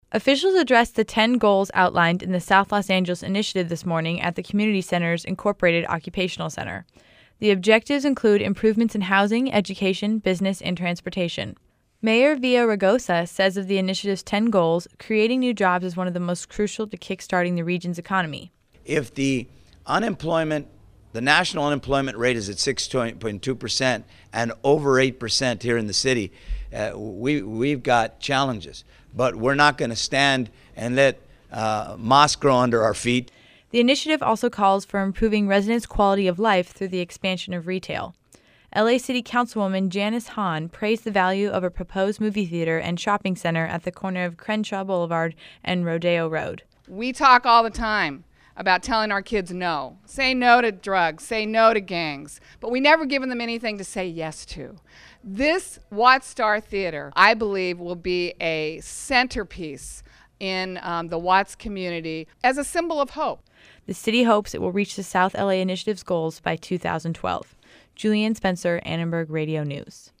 Mayor Antonio Villaraigosa and LA City Council members held a conference to discuss the main goals of a plan to improve life in South Los Angeles.